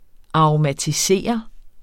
Udtale [ ɑʁomatiˈseˀʌ ]